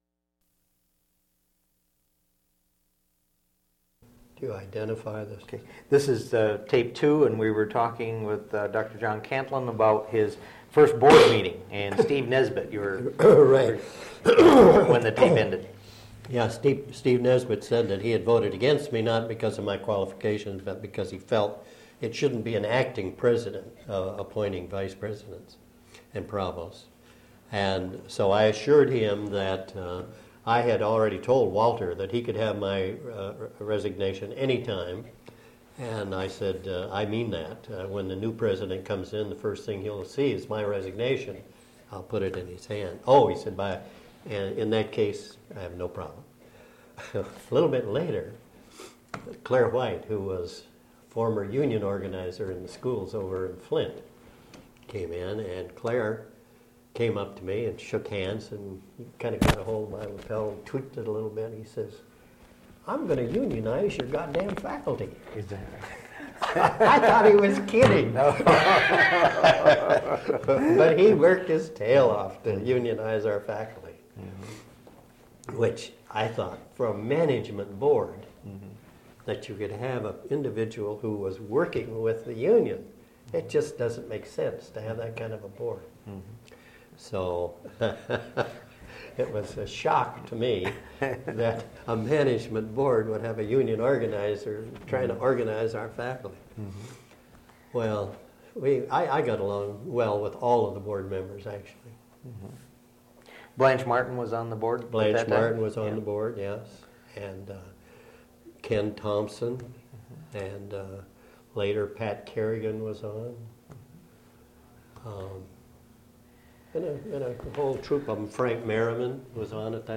Interview
Original Format: Audiocassettes